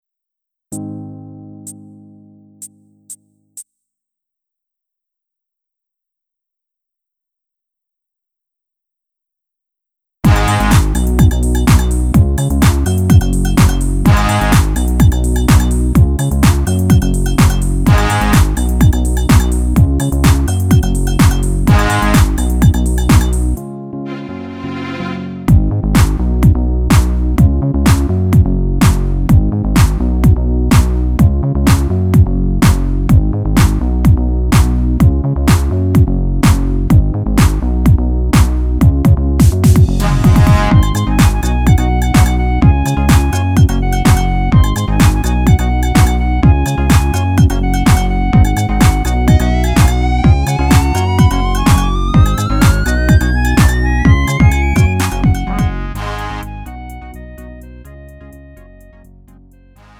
음정 -1키 3:23
장르 가요 구분